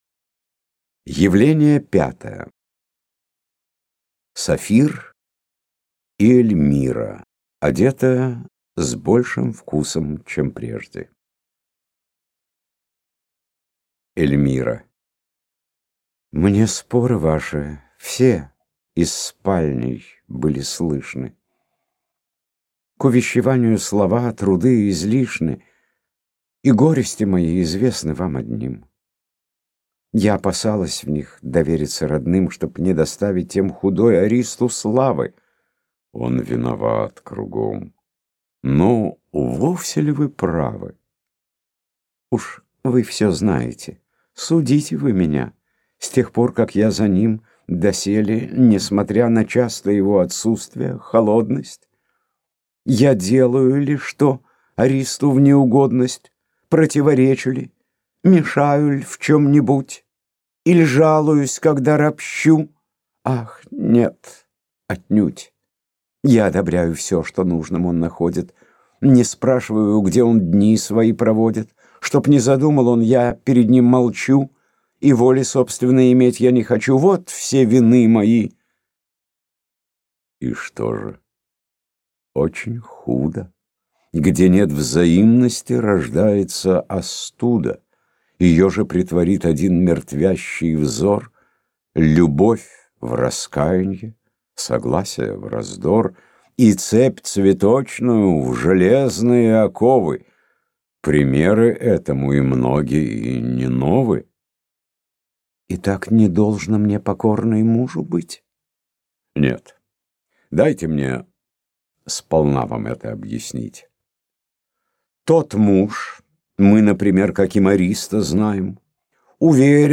Аудиокнига Горе от ума. Молодые супруги. Студент | Библиотека аудиокниг